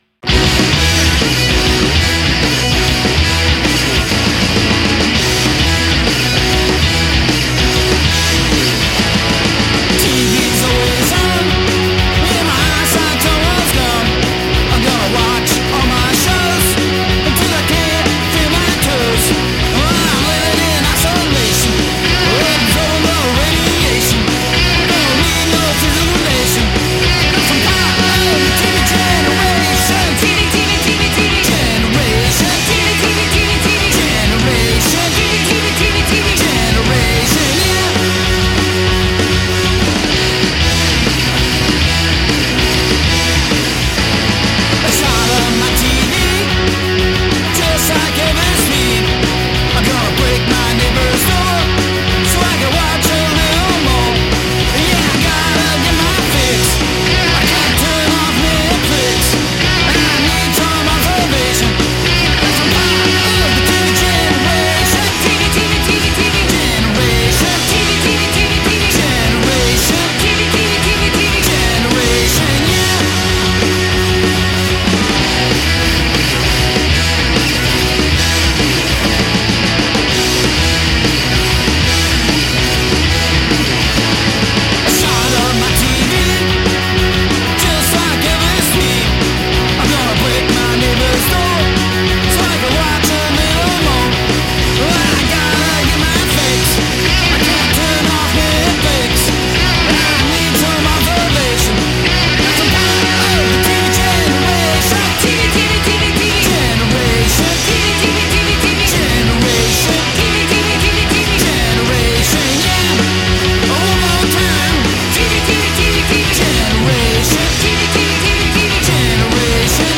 гаражный панк, бюджетный рок